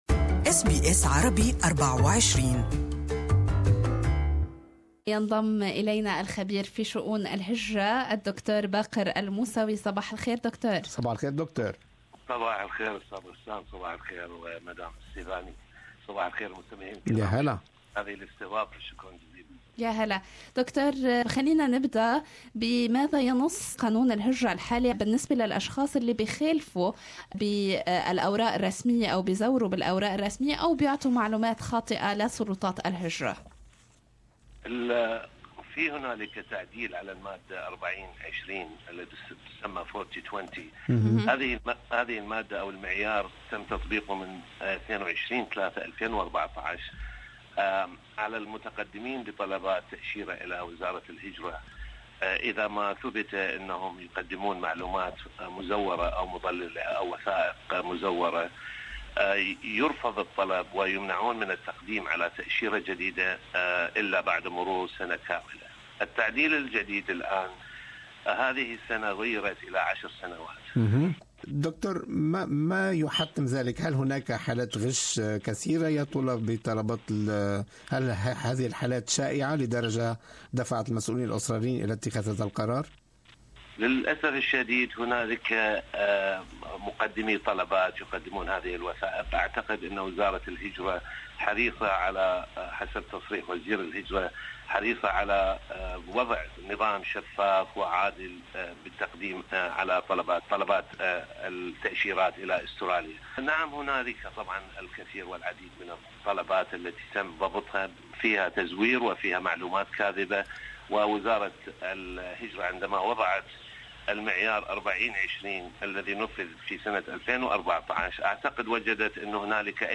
لسماع اللقاء الكامل أنقر على التدوين الصوتي أعلاه استمعوا هنا الى البث المباشر لاذاعتنا و لاذاعة BBC أيضا شارك